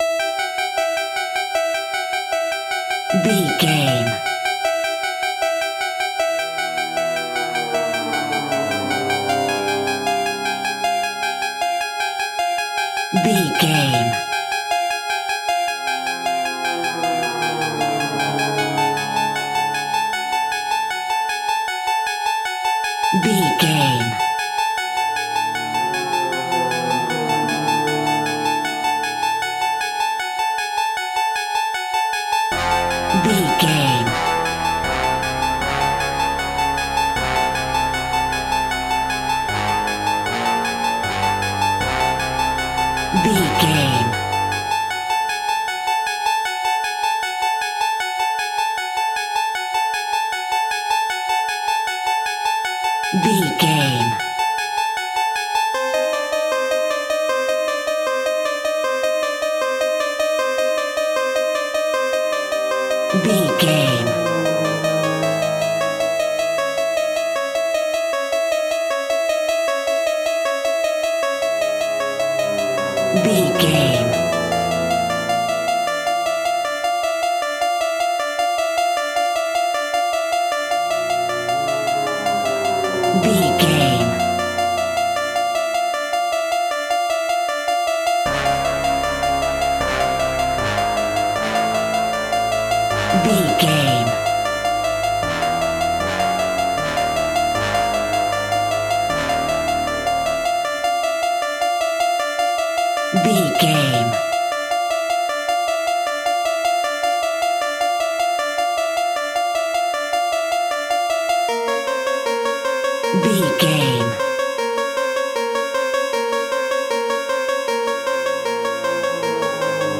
Retro 80s Horror Game Music.
In-crescendo
Thriller
Aeolian/Minor
ominous
eerie
synthesiser
instrumentals
Horror Pads
Horror Synths